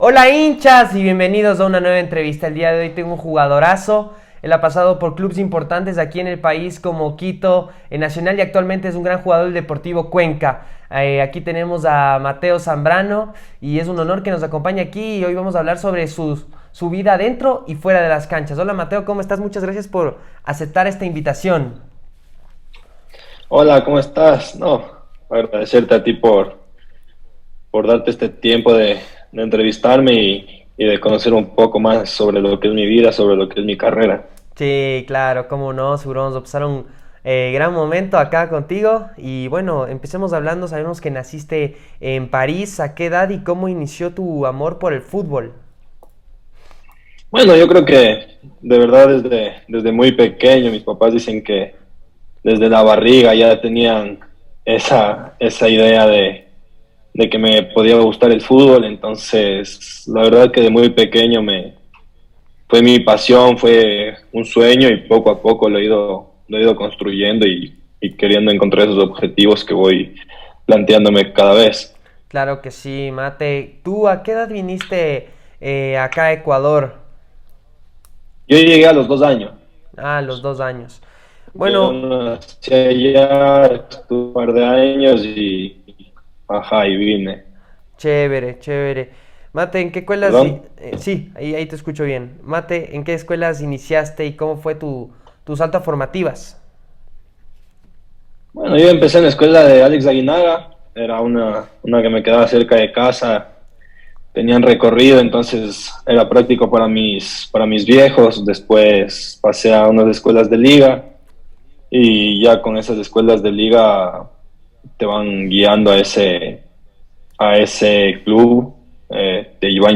Invitado